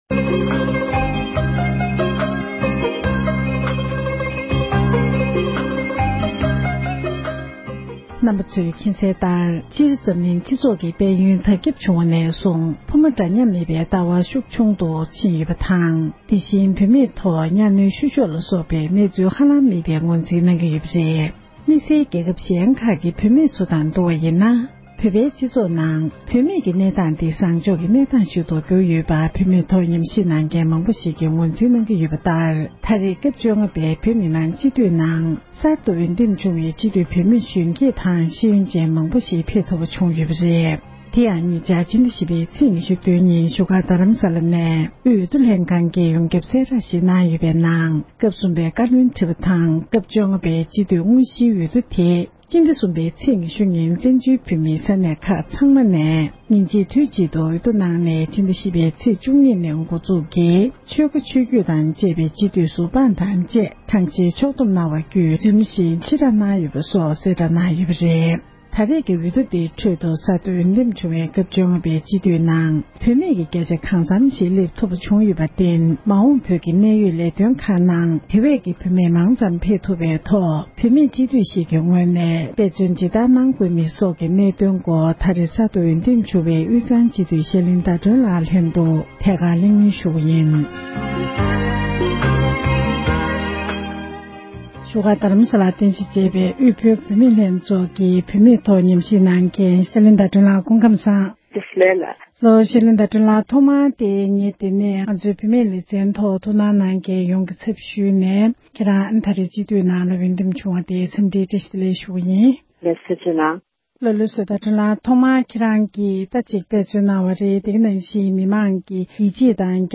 ཐད་ཀར་གནས་འདྲི